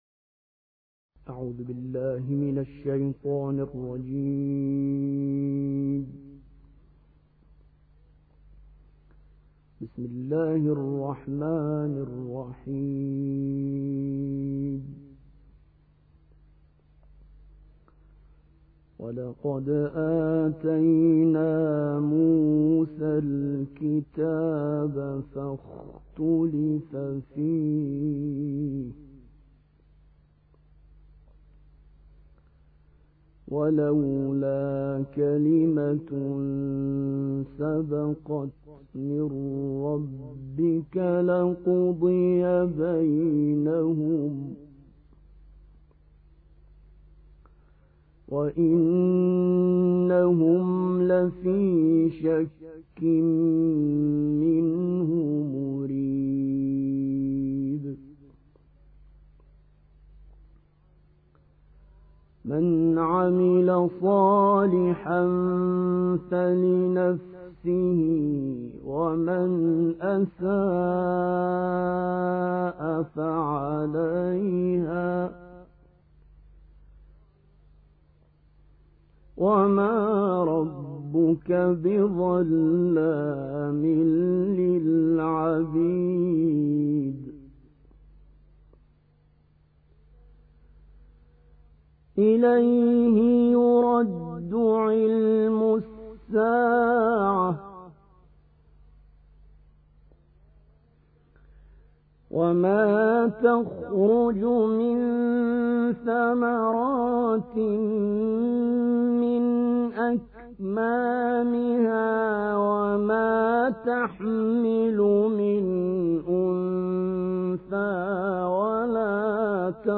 تکیه | تلاوت سوره فصلت آیه 45 تا آخر